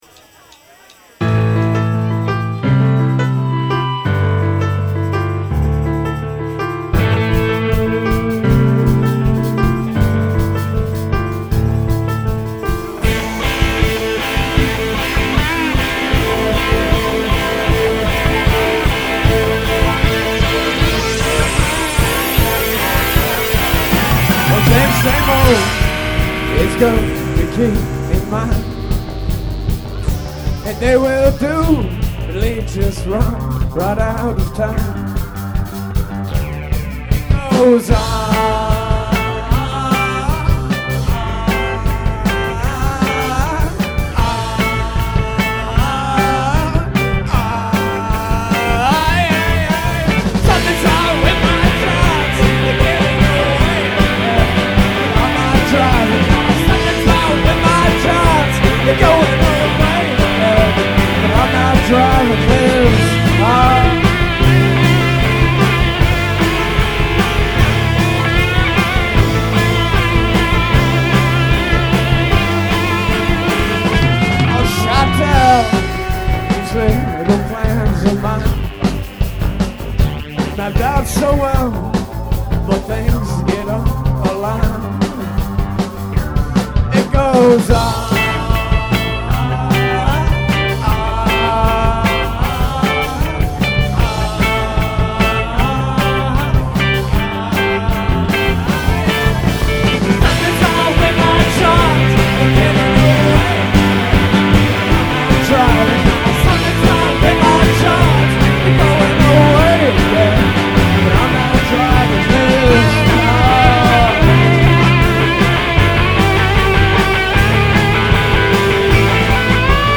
This is a relatively new song live at Pianos in NYC.
guitar
bass
drums, and I’m on keys and backup vocals.